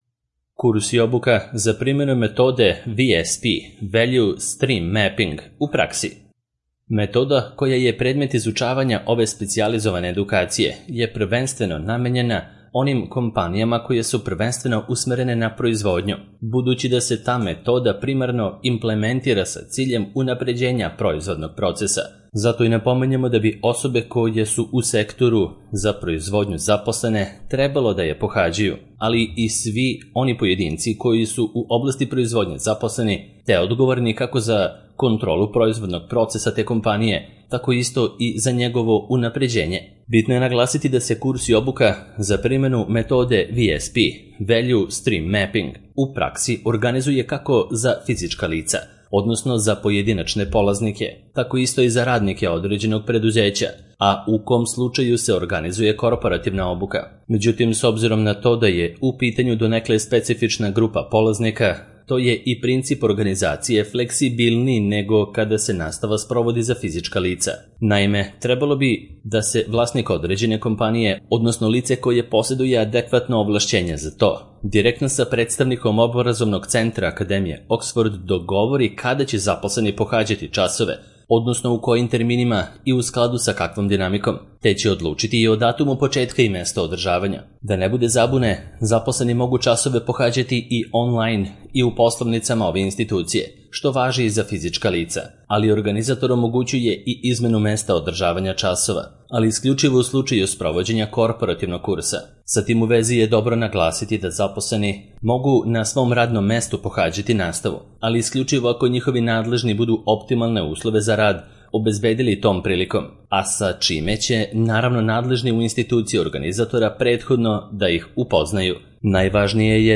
Primena metode VSP (Value Stream Mapping) u praksi - Audio verzija